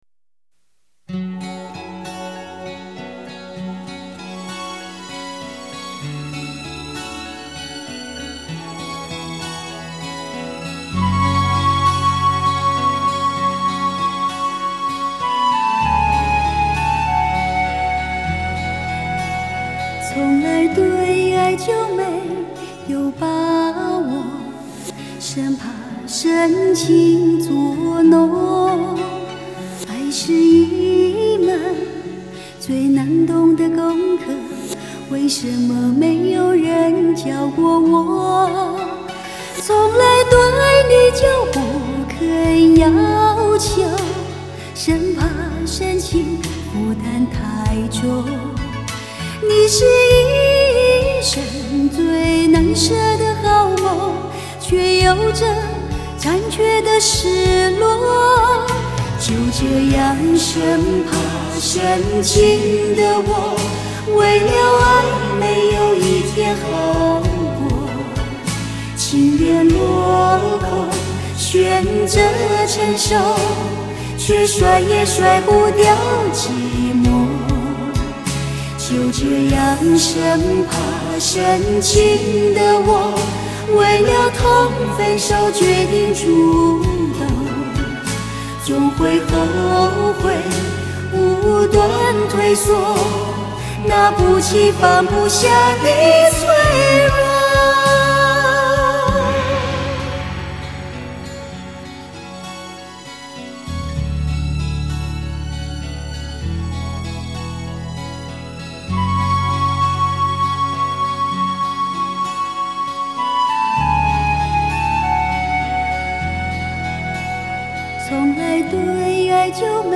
44.100 Hz;16 Bit;立体声